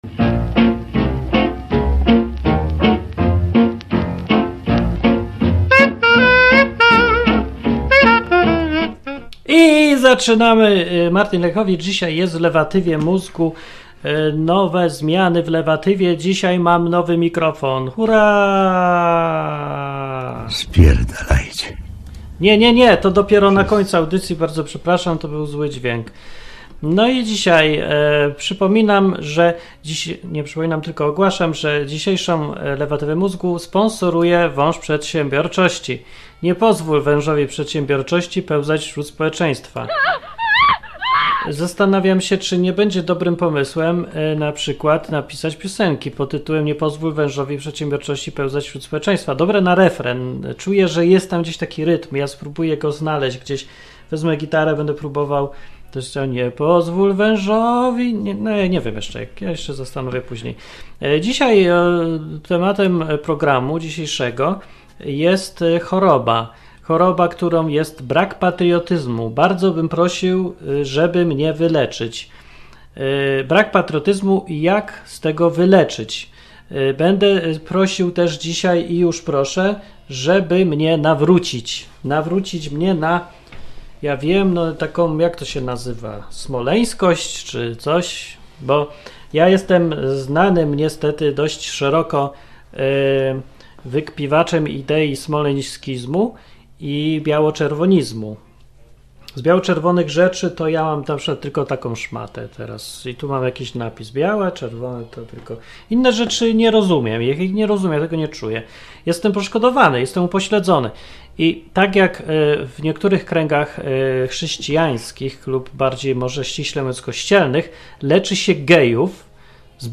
Program satyryczny, rozrywkowy i edukacyjny.
Ta audycja jest na żywo .